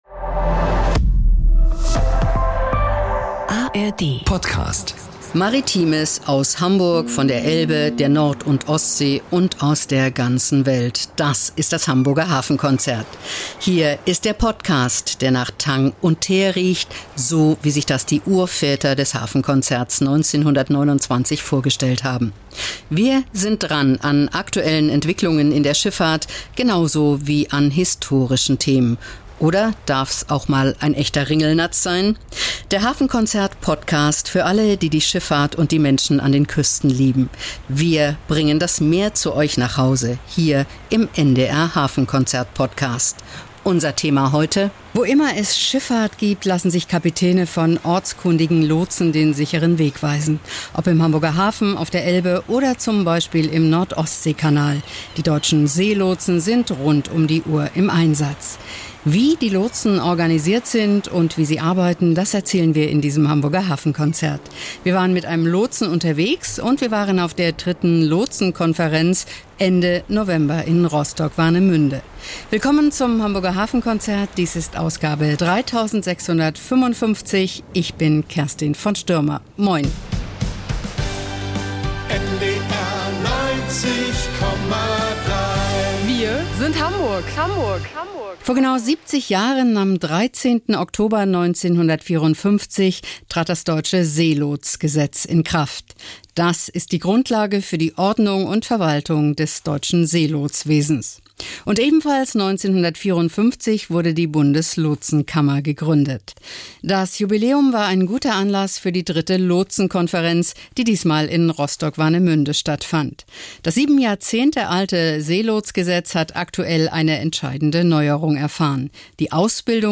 Spannende Reportagen und exklusive Berichte rund um den Hamburger Hafen, die Schifffahrt und die norddeutsche Geschichte.